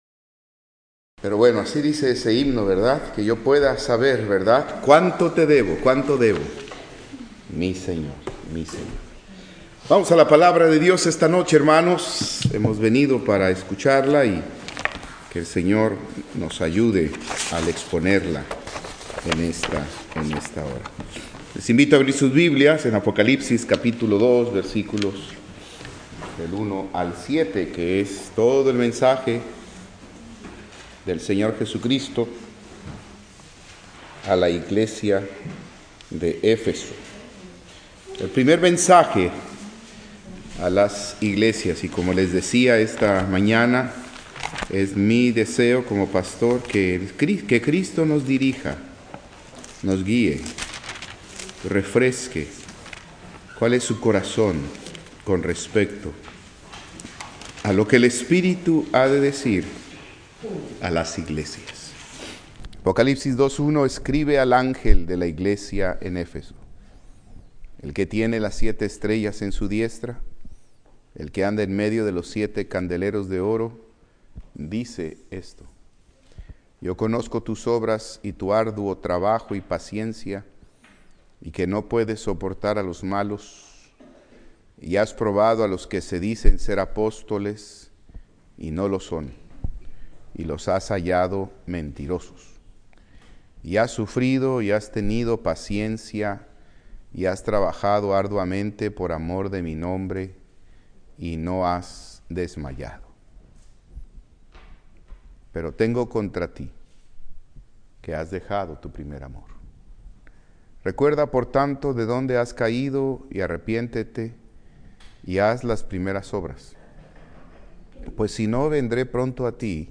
Servicio Vespertino